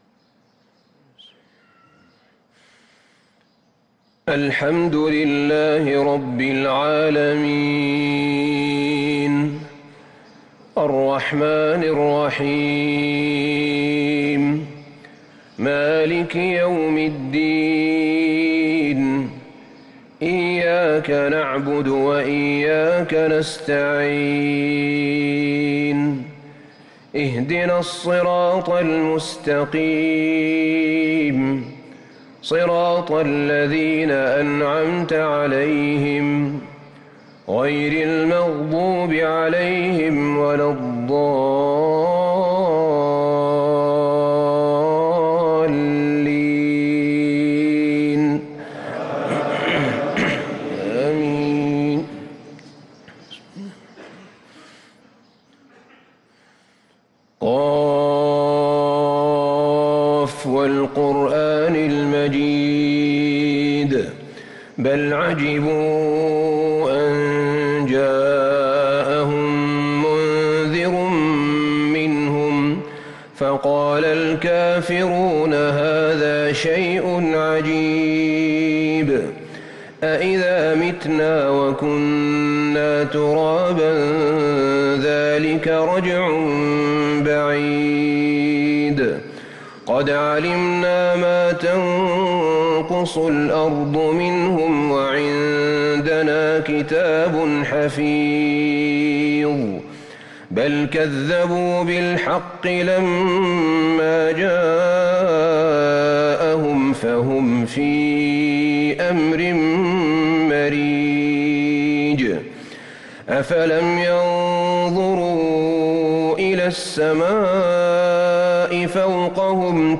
صلاة الفجر للقارئ أحمد بن طالب حميد 24 رمضان 1444 هـ
تِلَاوَات الْحَرَمَيْن .